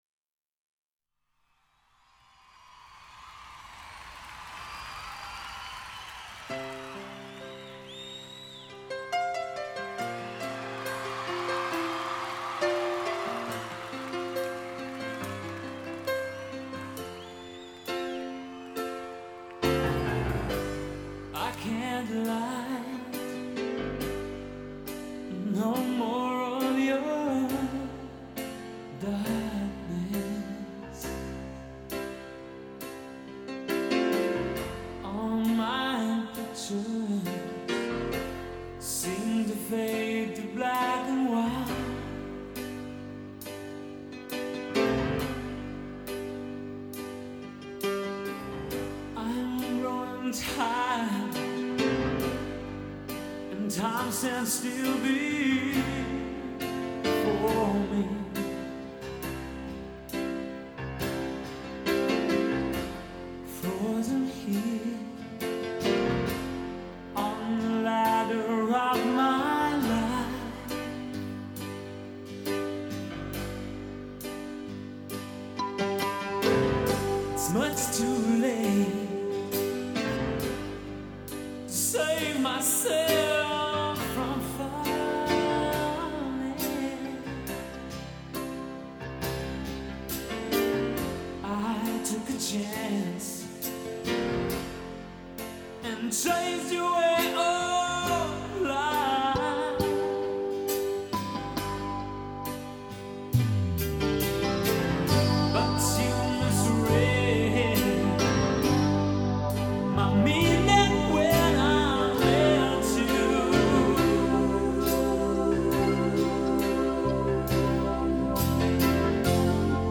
Концертный.